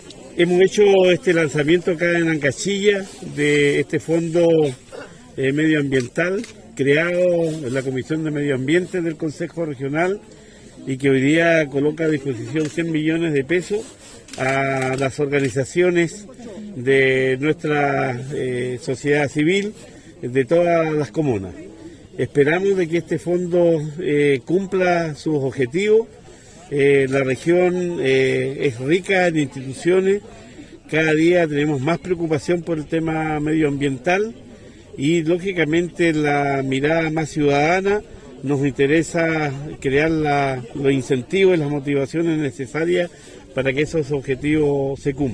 Cuna-Gobernador-Fondo-Medio-Ambiente.mp3